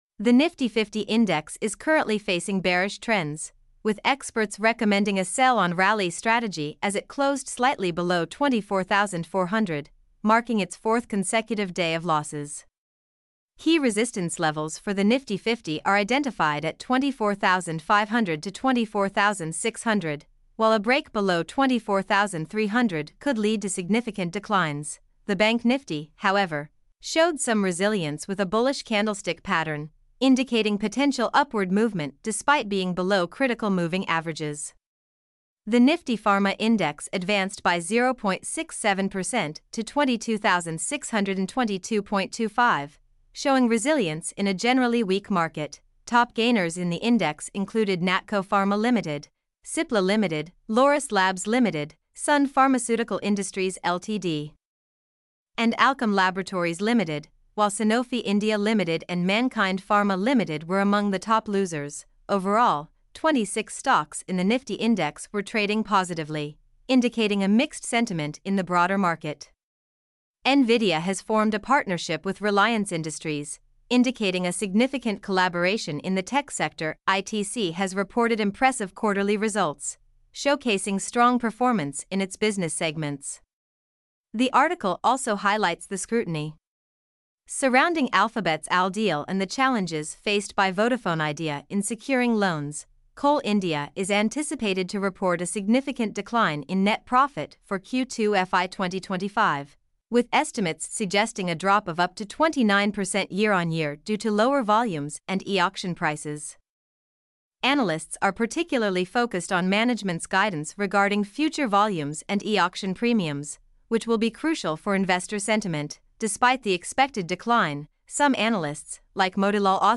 mp3-output-ttsfreedotcom-16.mp3